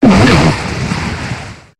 Cri de Boréas dans Pokémon HOME.